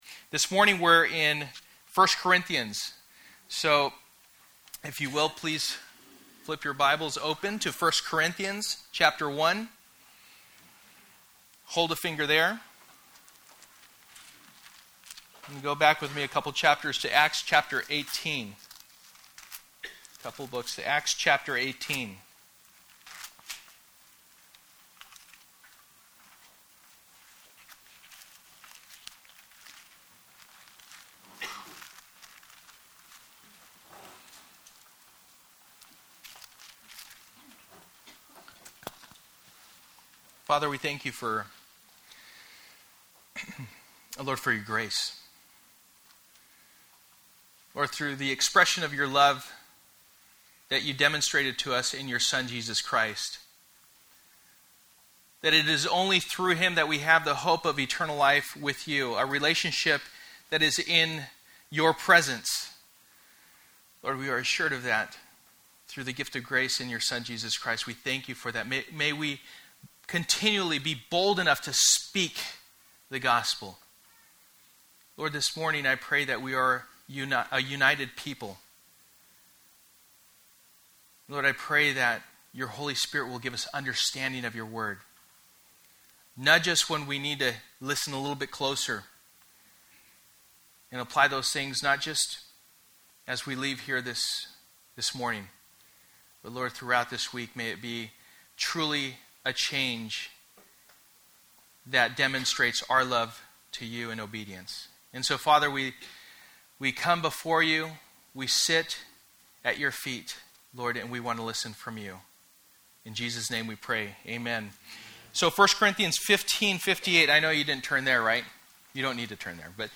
Sold Out Passage: 1 Corinthians 1:1-31 Service: Sunday Morning %todo_render% « N52